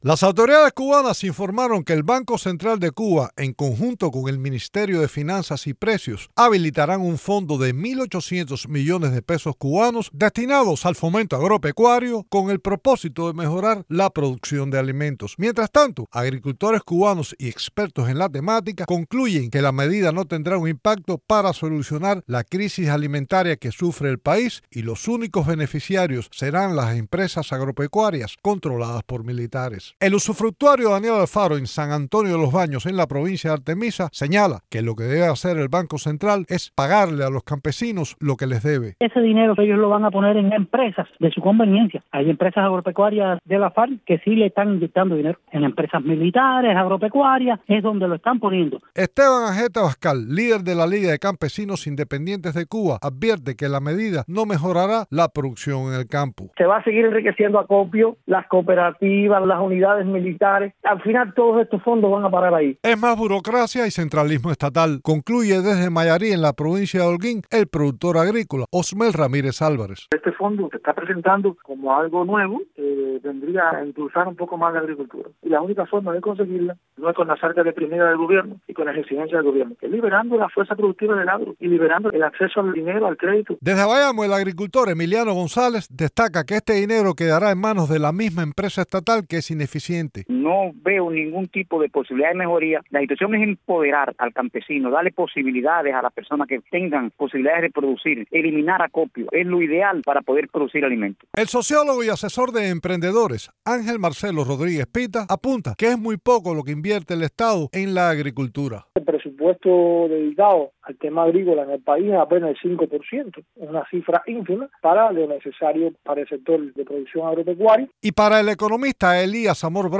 Mientras tanto, agricultores y expertos en la temática, dijeron a Radio Televisión Martí, que la medida no tendrá un impacto para solucionar la crisis alimentaria que sufre el país y que los únicos beneficiarios serán las empresas agropecuarias controladas por los militares.
Reportaje